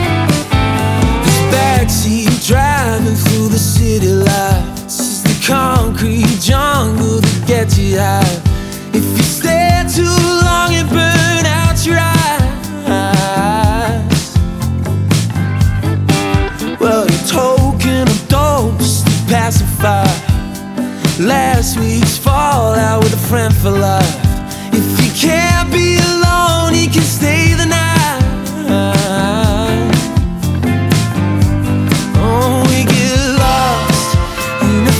Жанр: Иностранный рок / Рок / Инди
# Indie Rock